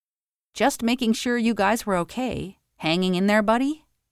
Como sempre, você dispõe da nossa série de exemplos (frases gravadas por nativos) para realmente fixar o uso em contexto da expressão.